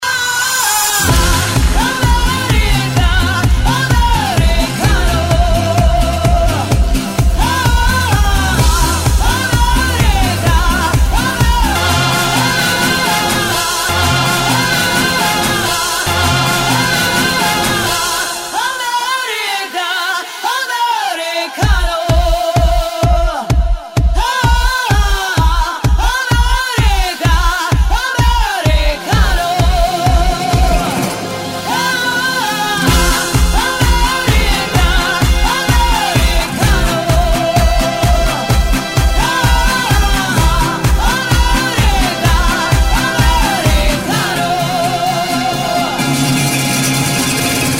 есть песня с таким проигрышем